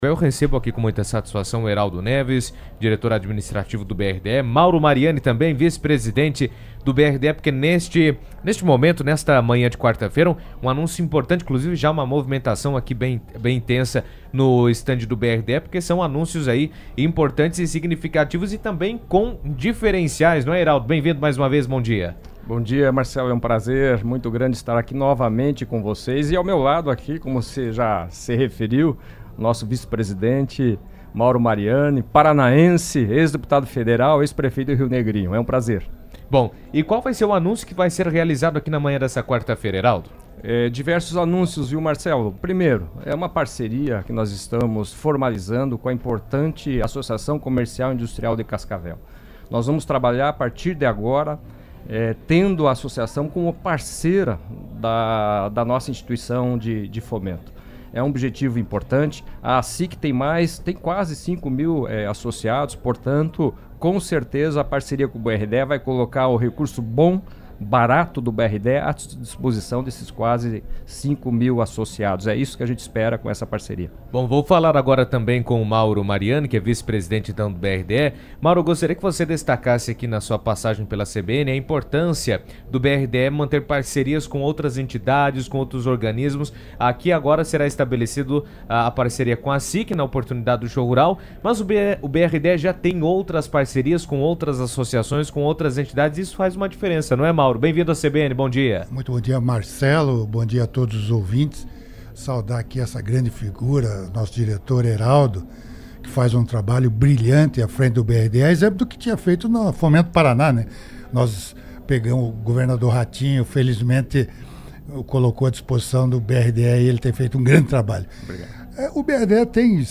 estiveram no estúdio da CBN durante a 38ª edição do Show Rural Coopavel e falaram sobre o convênio assinado com a Associação Comercial e Industrial de Cascavel